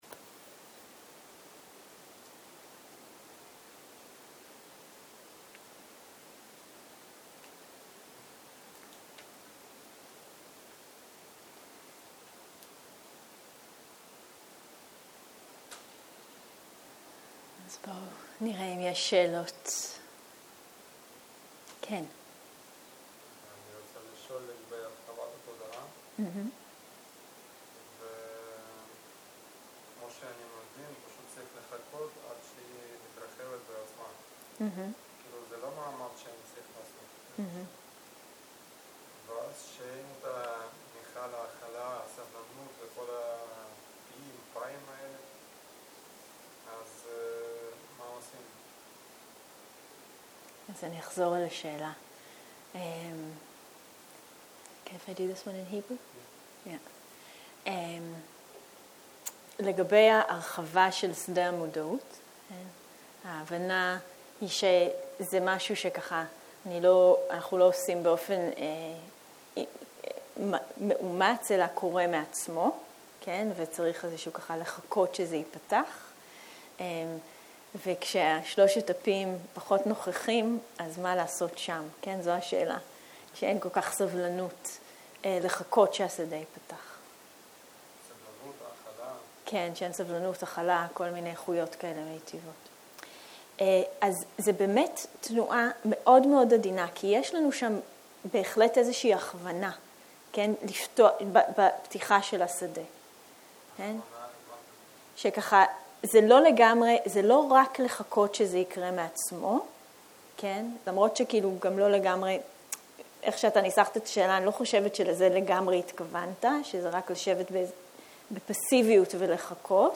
סוג ההקלטה: שאלות ותשובות